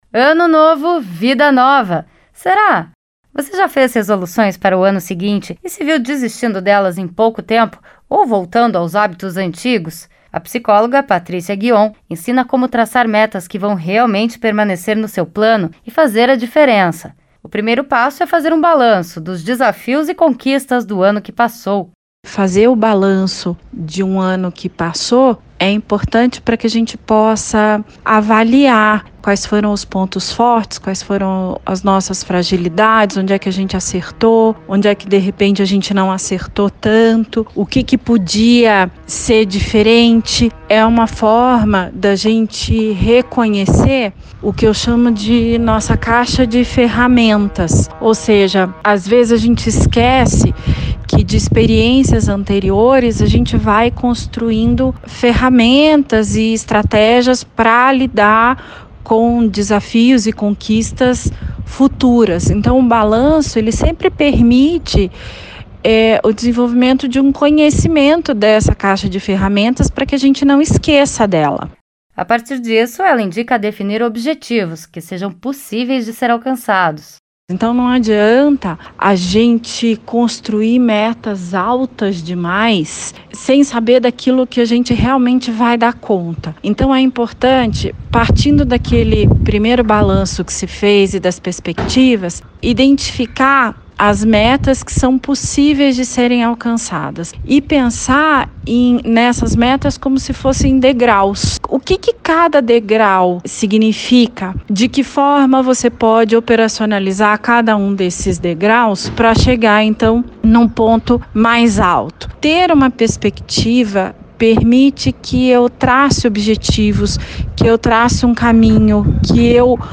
A reportagem traz dicas para saber como criar metas que vão realmente trazer resultados.